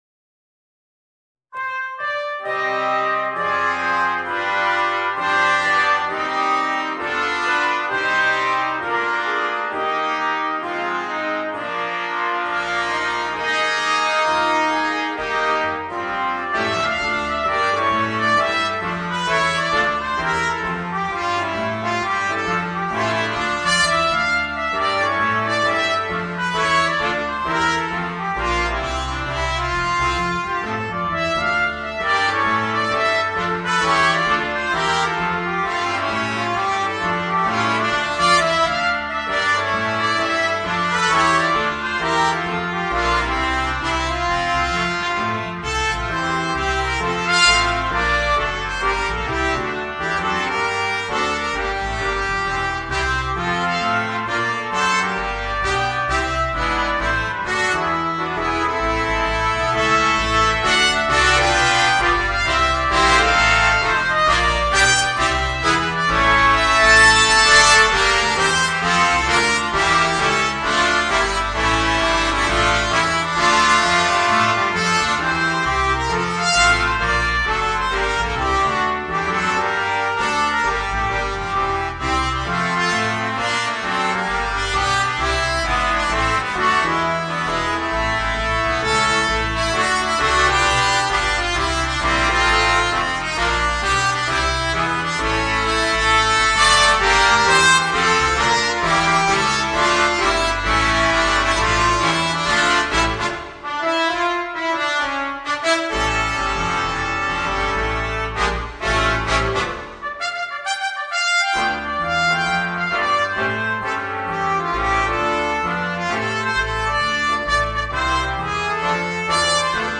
Voicing: 5 Trumpets and Piano